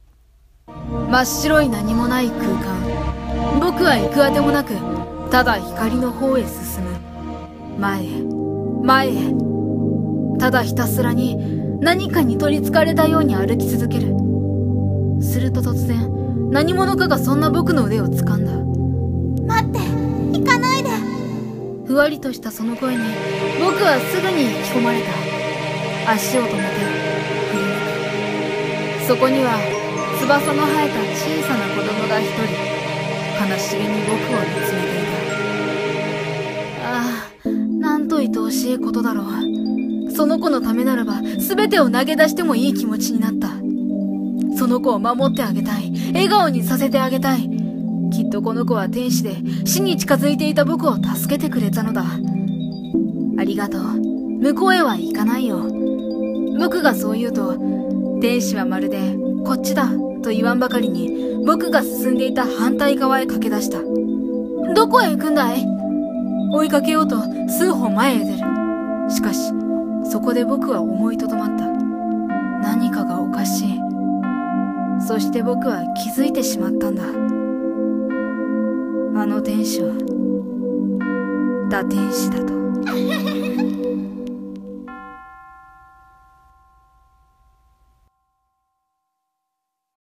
【１人声劇】堕天使の誘い